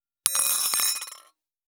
250,食器にスプーンを置く,ガラスがこすれあう擦れ合う音,
コップ